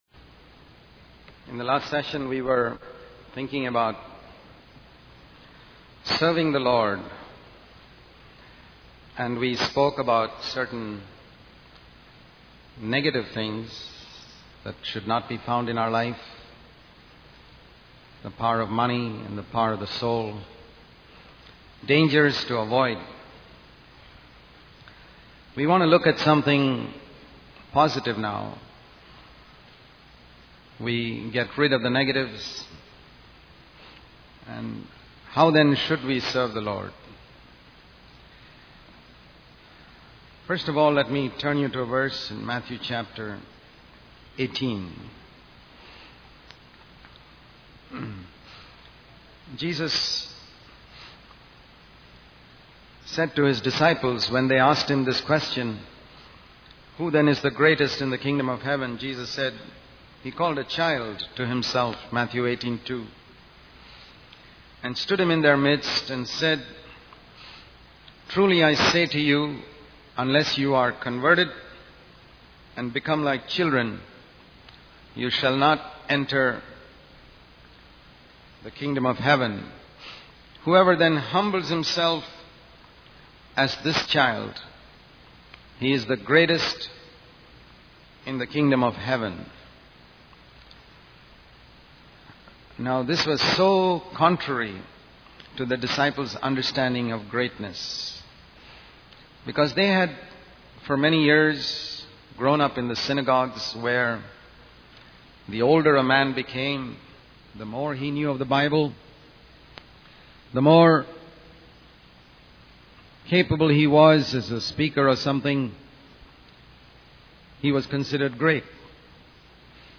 In this sermon, the speaker emphasizes the importance of serving the Lord and highlights three aspects of serving Him. Firstly, he warns against negative influences such as the power of money and the power of the soul. Secondly, he encourages believers to have a childlike and servant-hearted attitude in their service to God.